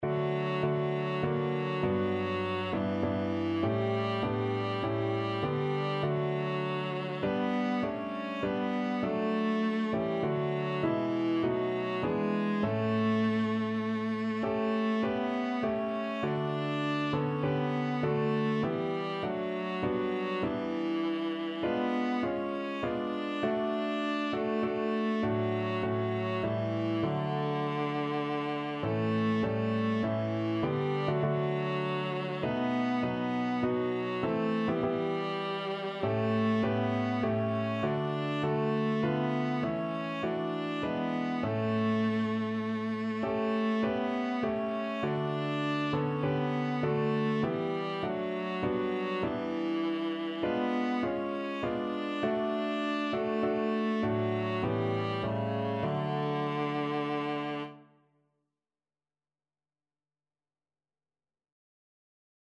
Db4-D5
3/4 (View more 3/4 Music)
Classical (View more Classical Viola Music)